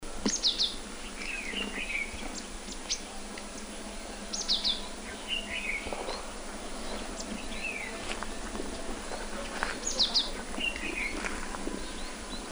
Mésange cinereus (Parus cinereus) Ssp commixtus
Cris enregistrés le 25 janvier 2012, en Chine, province du Guangxi, à Shanli près de la ville de Yizhou.
Cris de Mésange cinereus : écouter ici